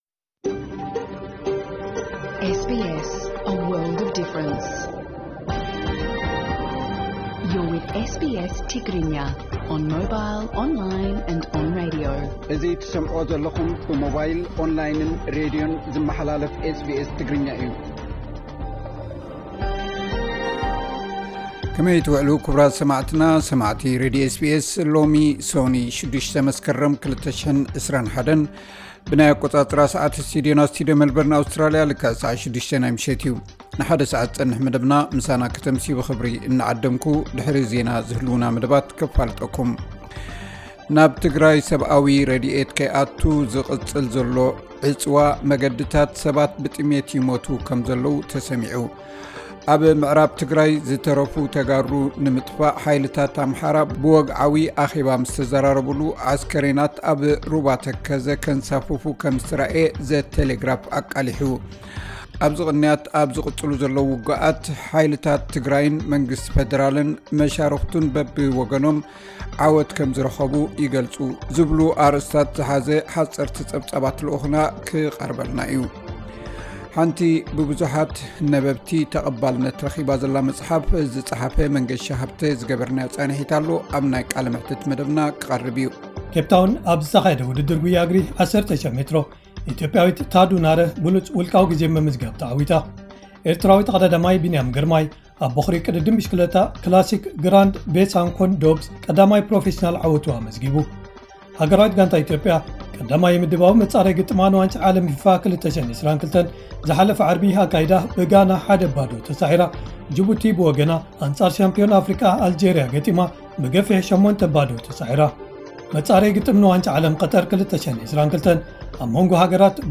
ዕለታዊ ዜና 06 መስከረም 2021 SBS ትግርኛ